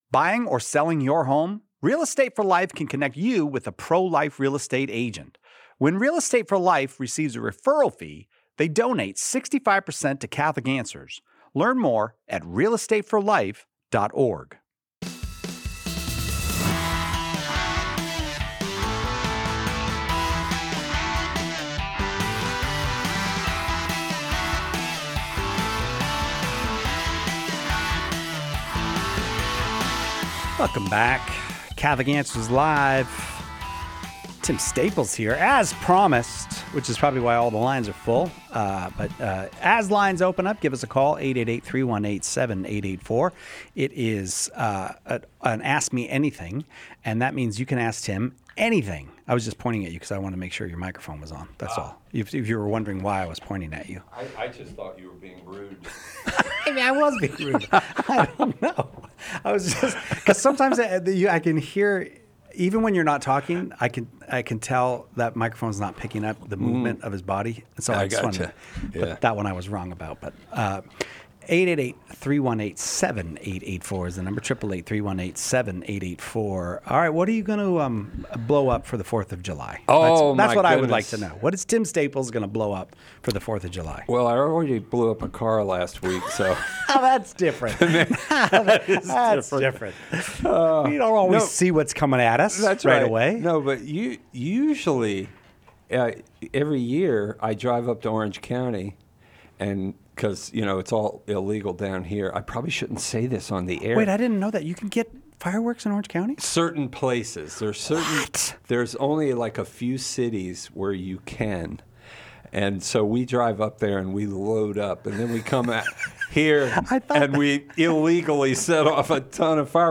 In this episode of Catholic Answers Live , listeners ask thought-provoking questions on topics ranging from liturgy and Church history to spiritual warfare and vocational discernment. Questions include how to begin a career in Catholic apologetics, whether the Novus Ordo Mass assumes an ad orientem posture, and how exorcism practices differ between Catholics and non-Catholic Christians. Also discussed is the historical development of the priesthood and the formal distribution of Communion, tracing its evolution from house churches to today’s liturgical structure.